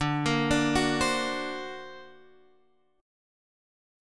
D+7 chord